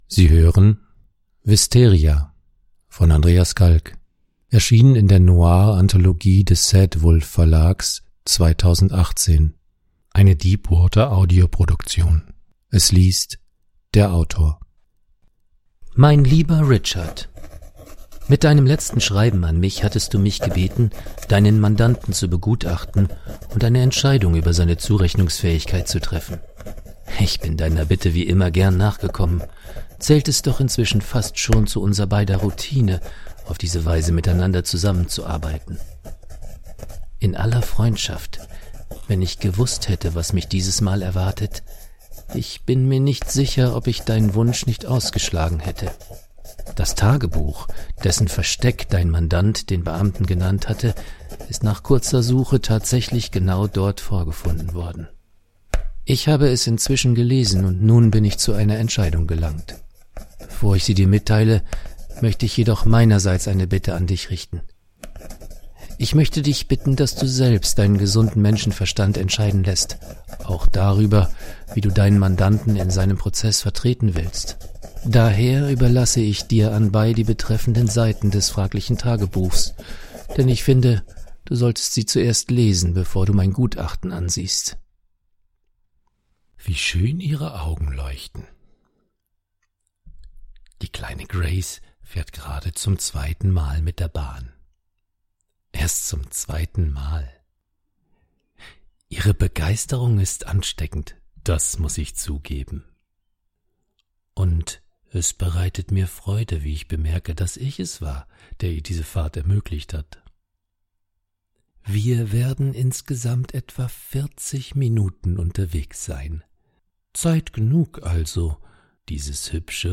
Autorenlesung.